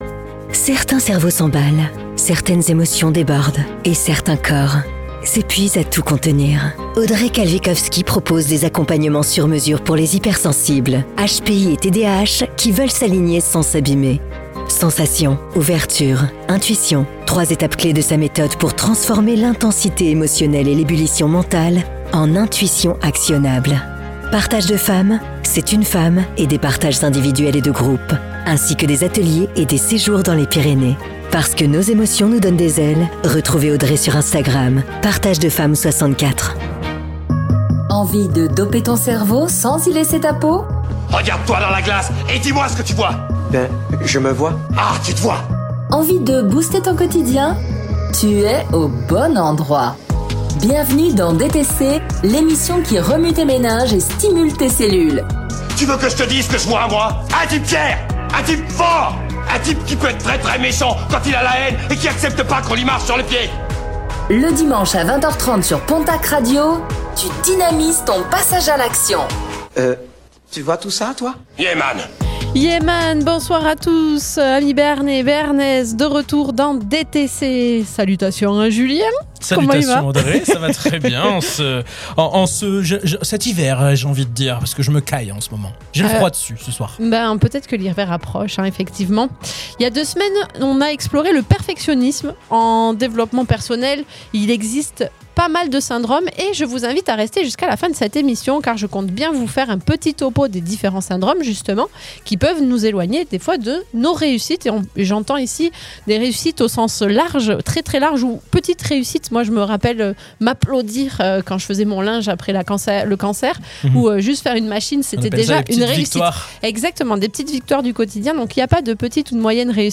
Réécoutez l'émission DTC : « Syndrome de l’imposteur : comment en faire un moteur et plus un frein ?